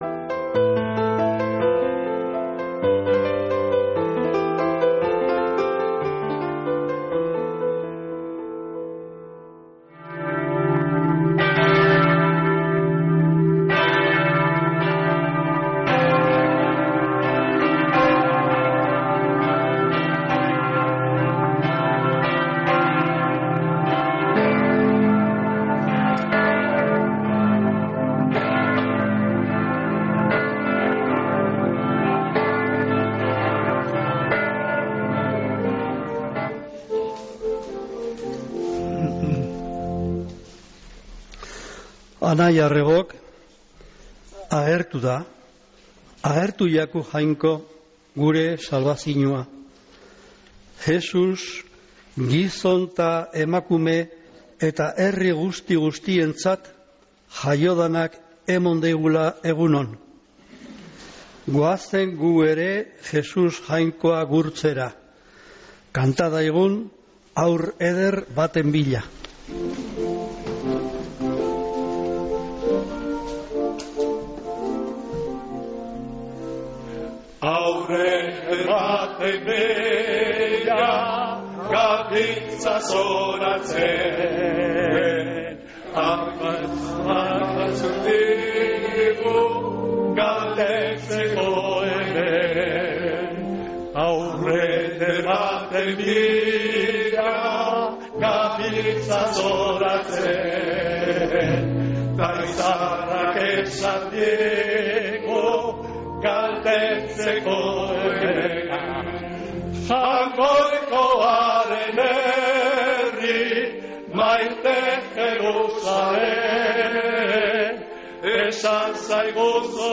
Mezea San Felicisimoko Eleizatik | Bizkaia Irratia